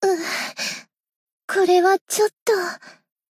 贡献 ） 分类:彩奈 分类:蔚蓝档案语音 协议:Copyright 您不可以覆盖此文件。
BA_V_Akane_Battle_Damage_1.ogg